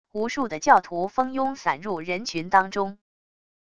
无数的教徒蜂拥散入人群当中wav音频生成系统WAV Audio Player